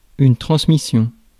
Ääntäminen
IPA: /tʁɑ̃s.mi.sjɔ̃/